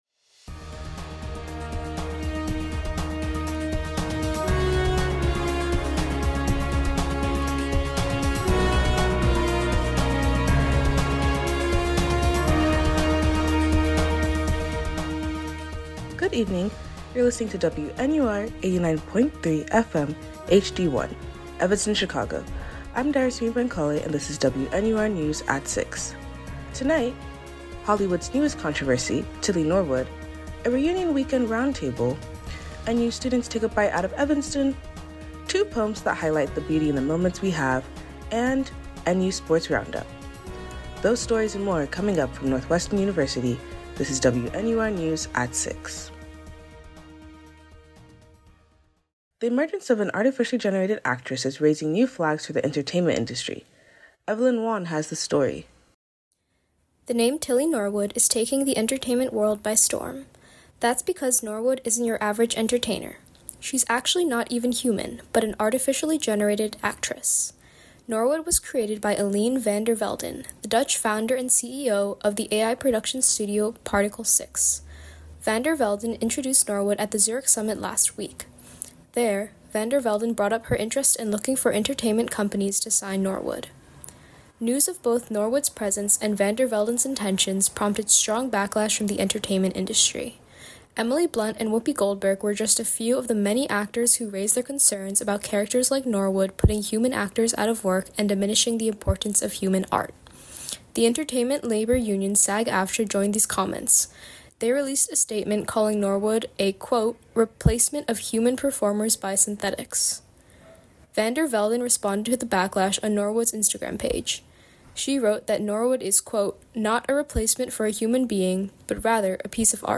WNUR News broadcasts live at 6 pm CST on Mondays, Wednesdays, and Fridays on WNUR 89.3 FM.